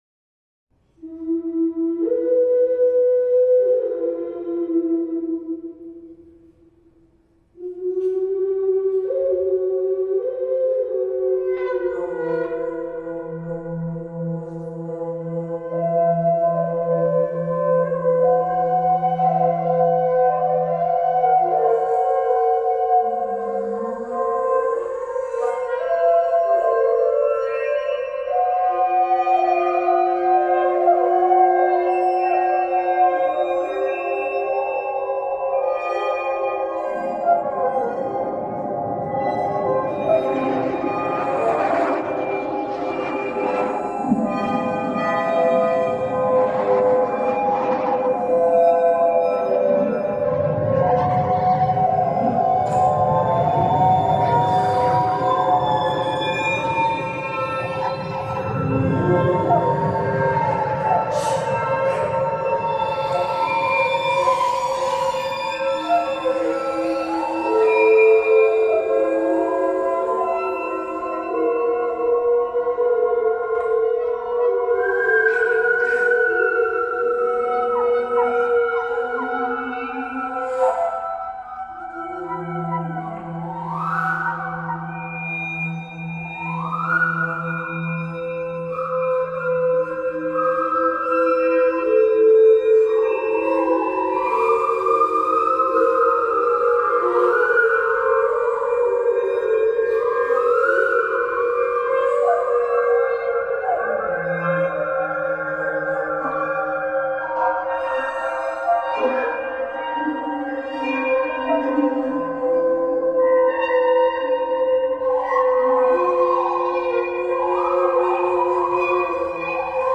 for bass flute and electronic media